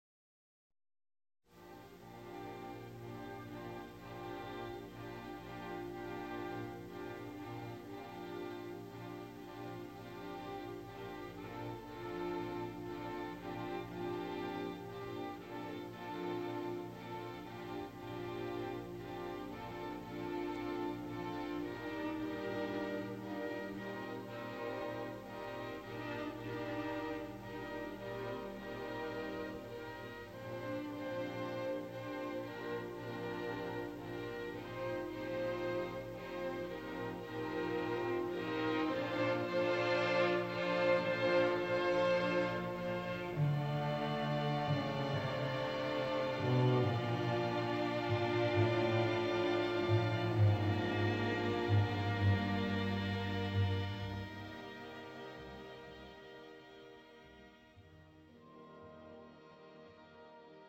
장르 뮤지컬 구분